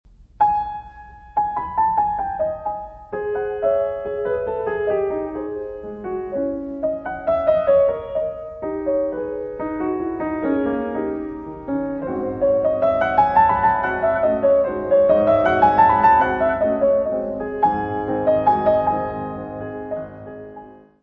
piano
Music Category/Genre:  Classical Music